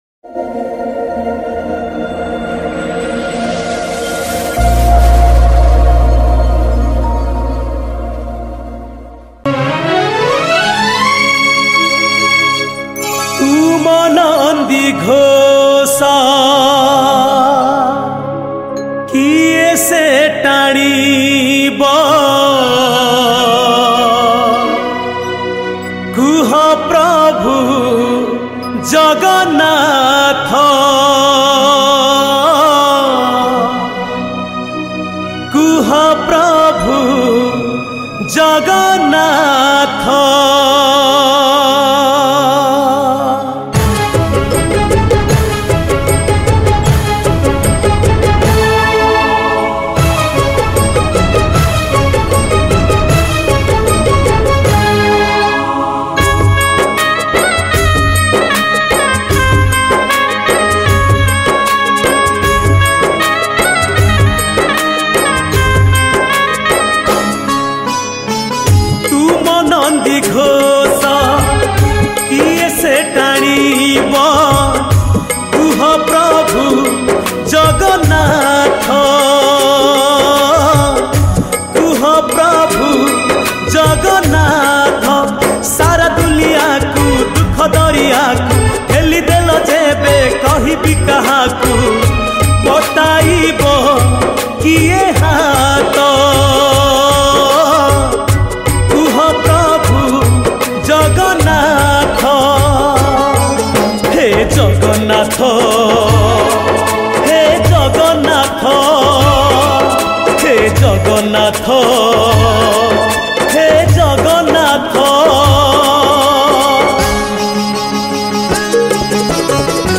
Category : Ratha Yatra Odia Bhajan 2023